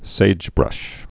(sājbrŭsh)